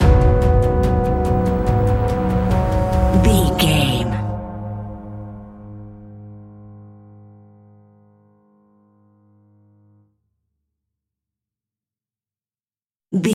Slow Moving Suspense Stinger.
Aeolian/Minor
A♭
ominous
dark
eerie
piano
synthesizer
horror music